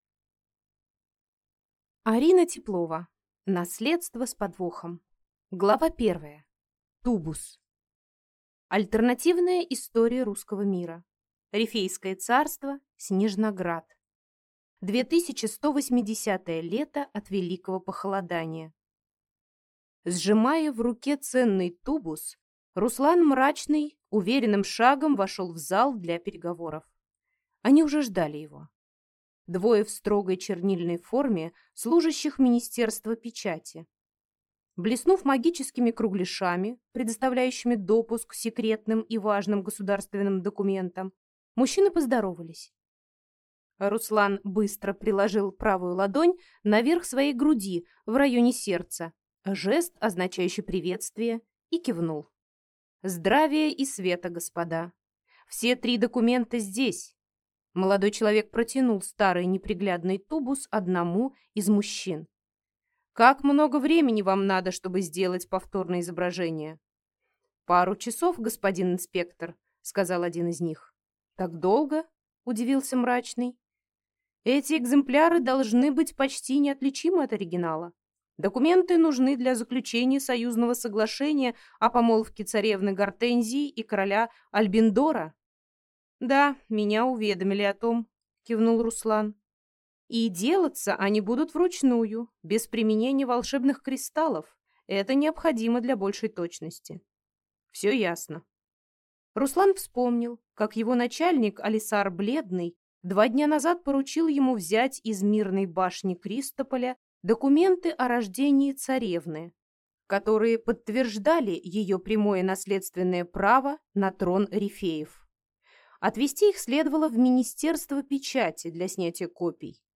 Аудиокнига Наследство с подвохом | Библиотека аудиокниг